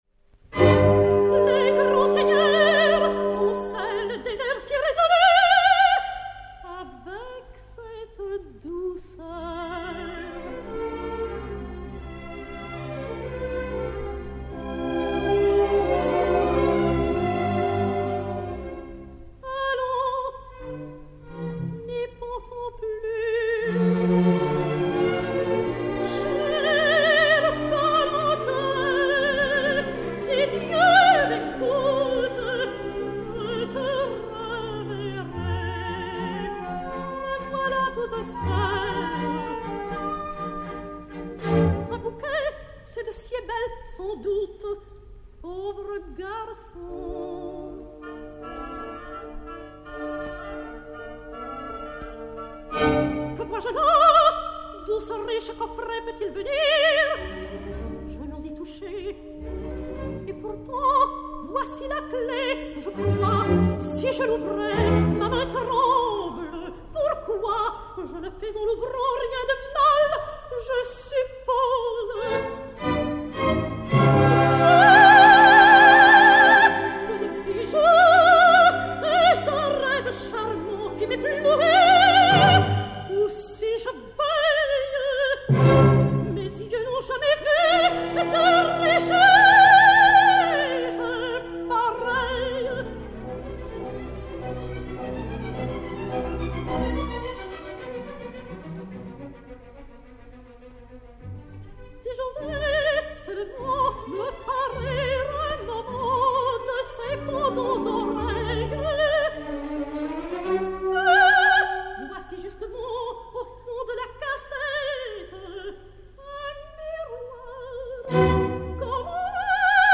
Renée Doria (Marguerite) et Orchestre Symphonique dir.